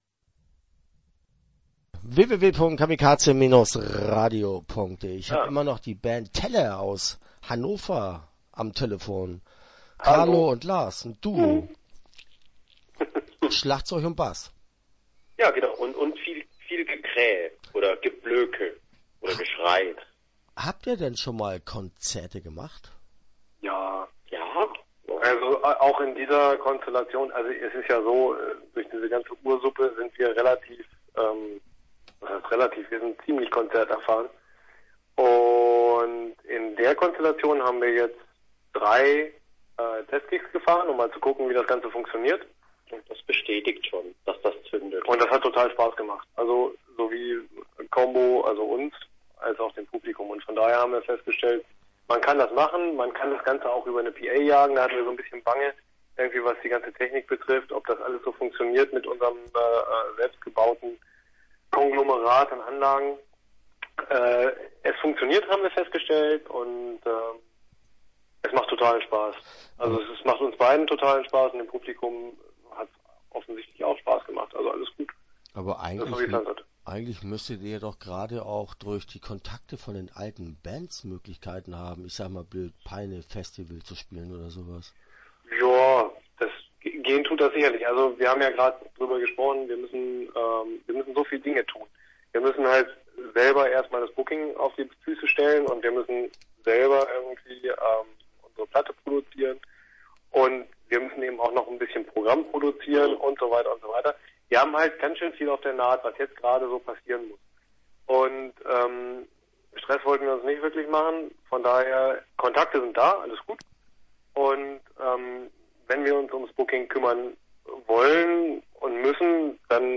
TLR - Interview Teil 1. (13:39)